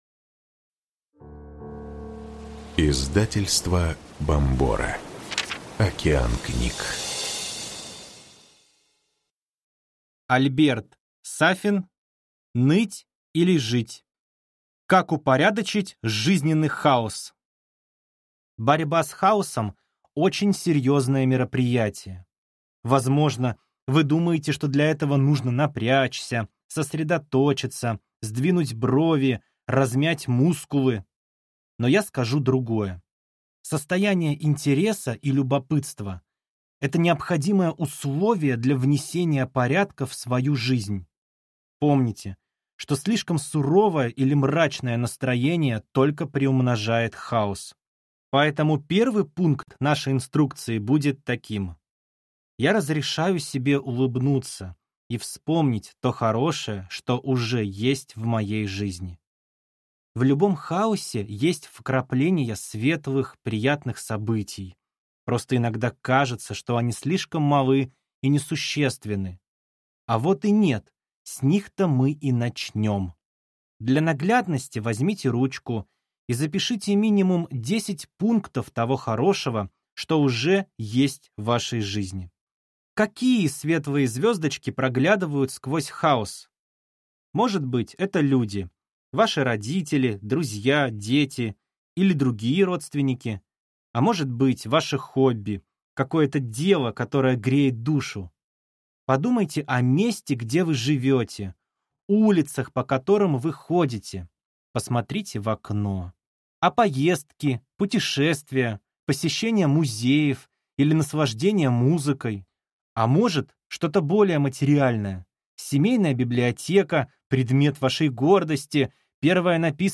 Аудиокнига Ныть или жить. Как упорядочить жизненный хаос | Библиотека аудиокниг